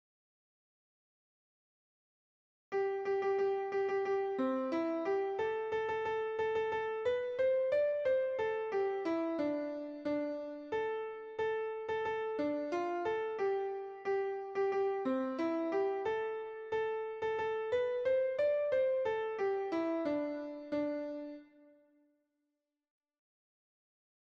Tenor 1